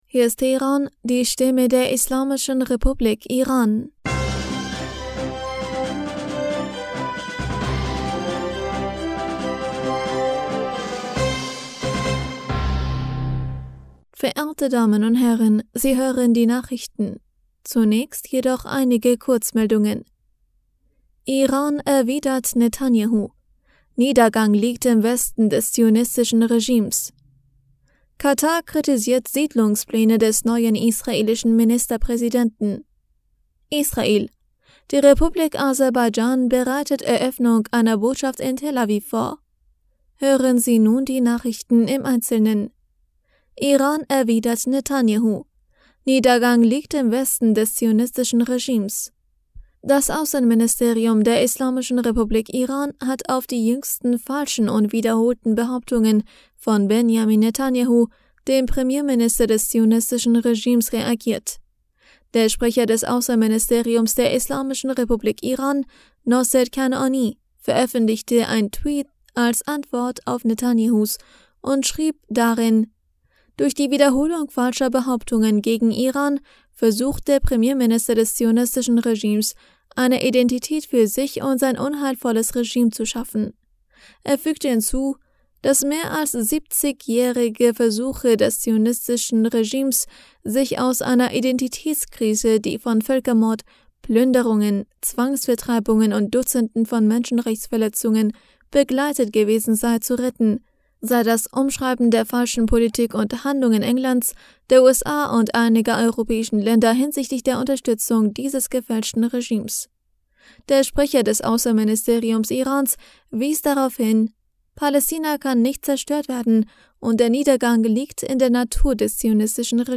Nachrichten vom 31. Dezember 2022